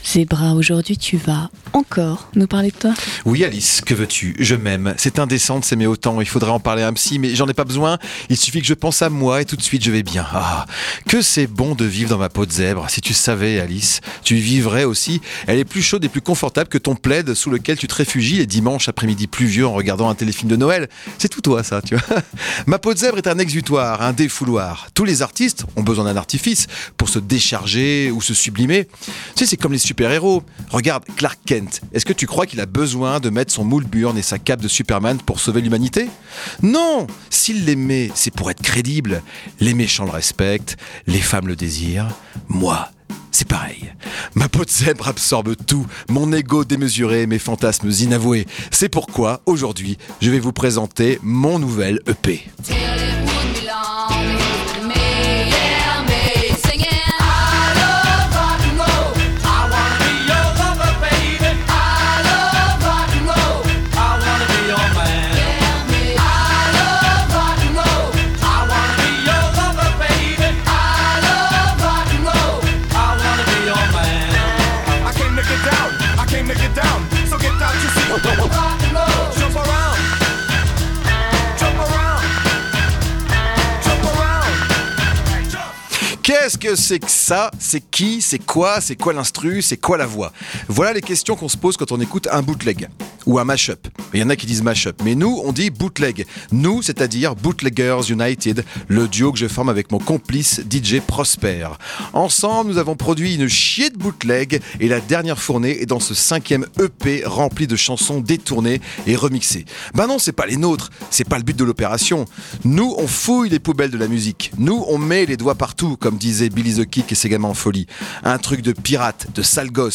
Chronique
Animation